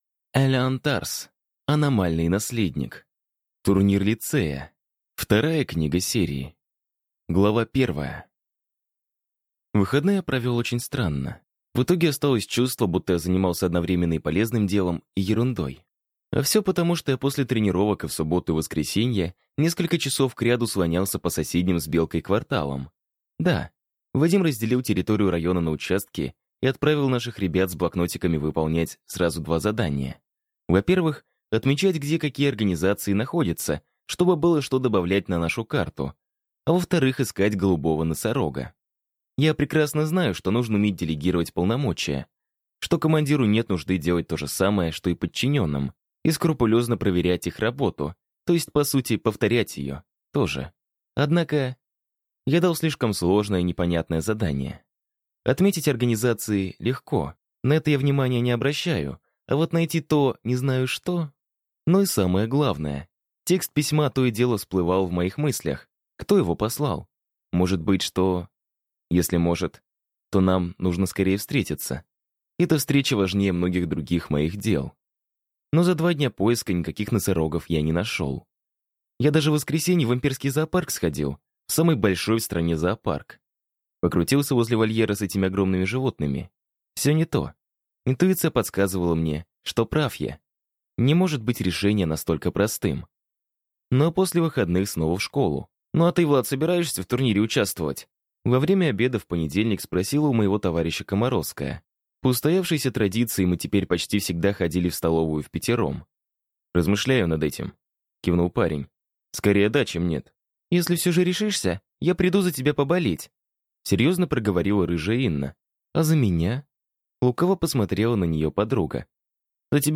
Аудиокнига Аномальный наследник. Турнир лицея | Библиотека аудиокниг